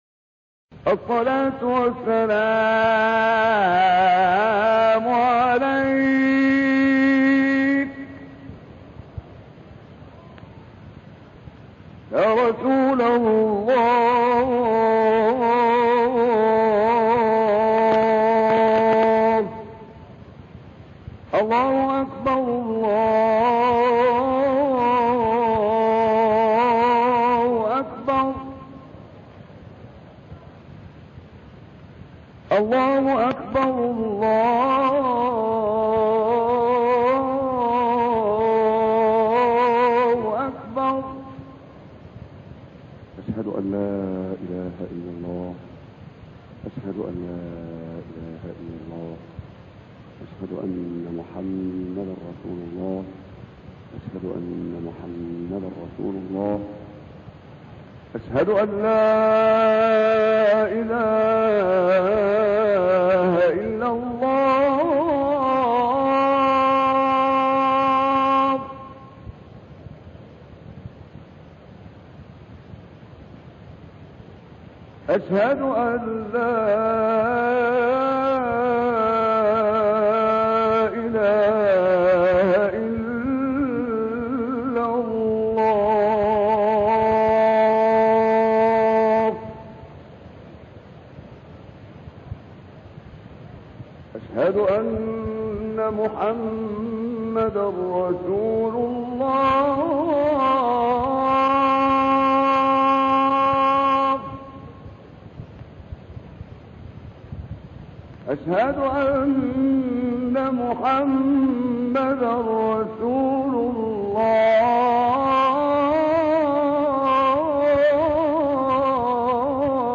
گروه فعالیت‌های قرآنی: گلبانگ اذان، با صدای دلنشین 9 قاری بین‌المللی را می‌شنوید.
اذان شیخ محمد عمران